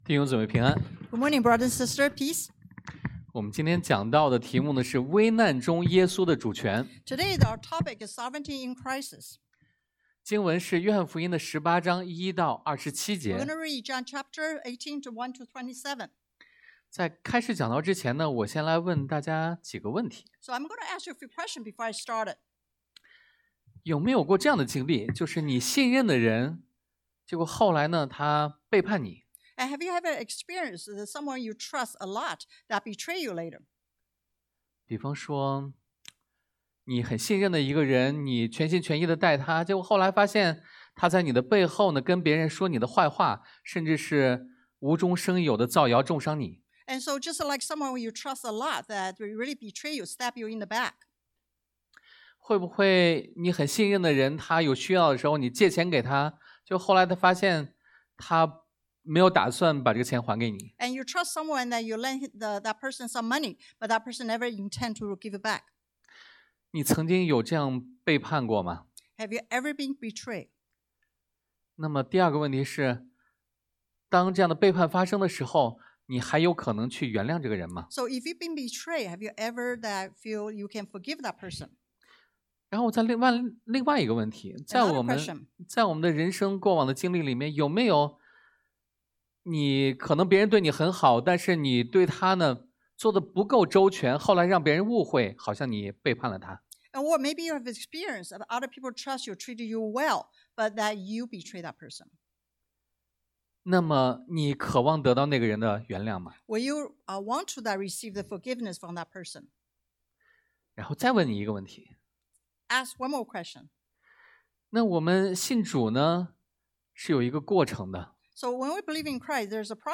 Passage: 約翰福音 John 18:1-27 Service Type: Sunday AM